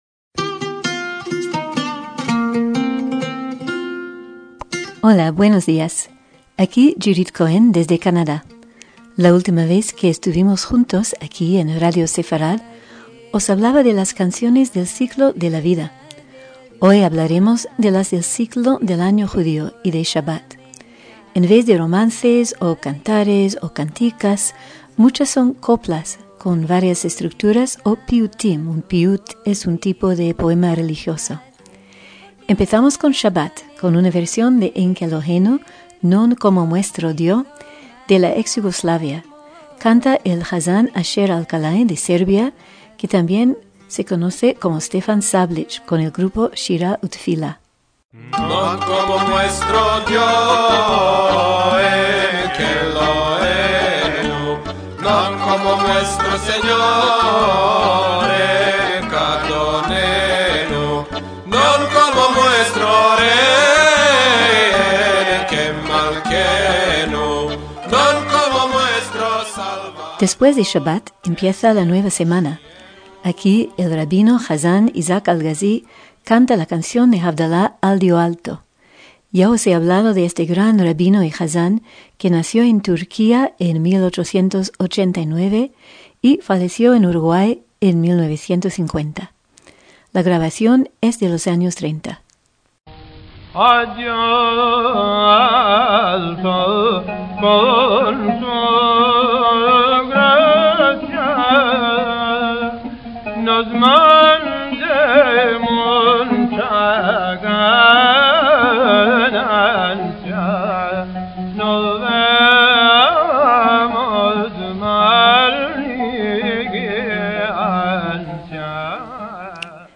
La grabación es de los años 30.